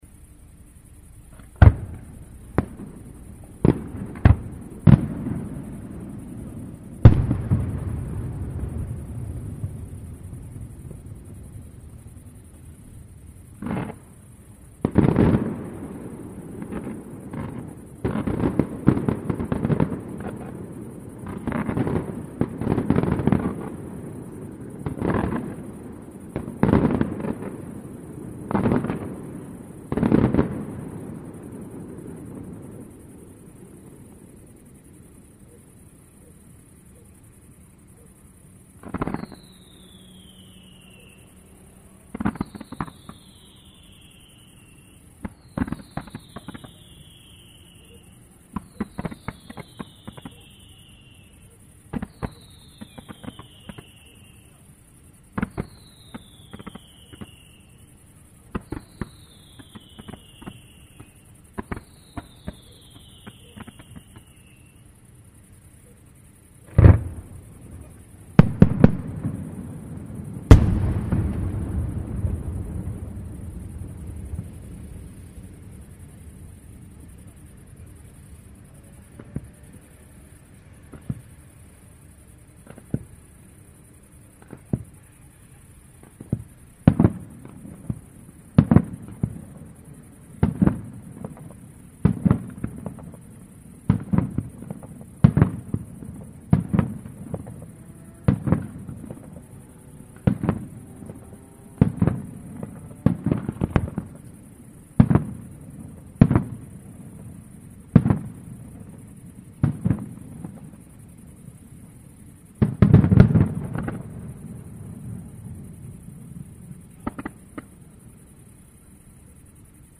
Fuochi d'artificio di San Giovanni
Fuochi.mp3